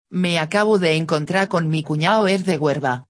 andaluz_02.mp3